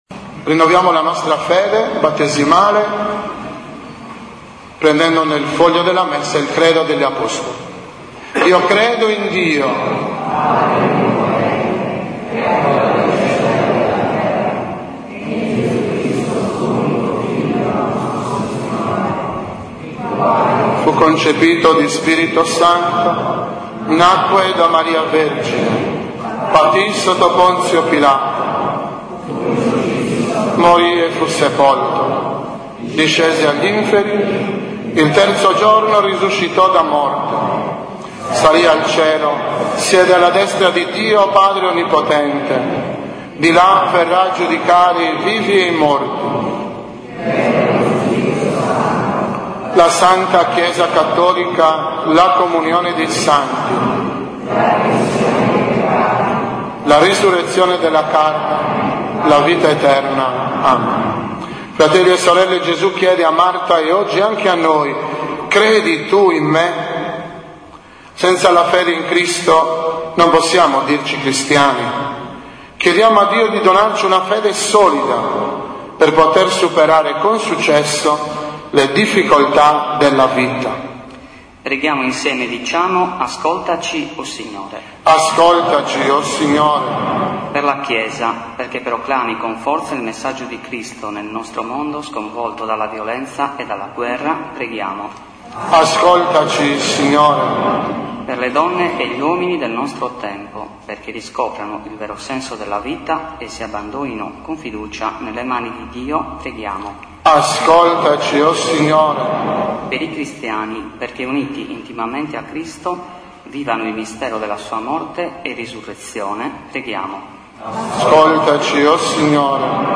Consacrazione, Riti di Comunione e Conclusione della V Domenica del Tempo di Quaresima e del Precetto Pasquale dei Giovani.